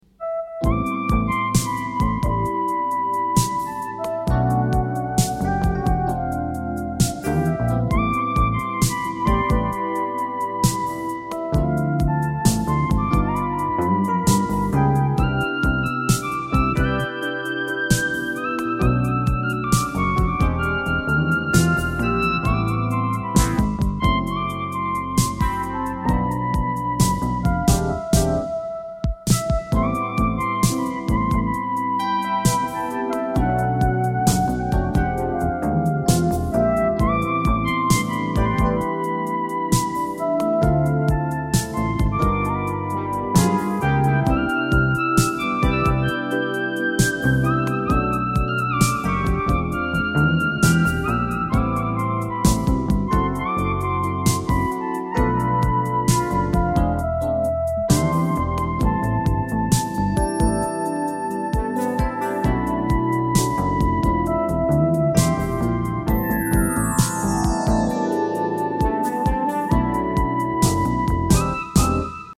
• Качество: 128, Stereo
спокойные
без слов
инструментальные
80-е
джаз
Smooth Jazz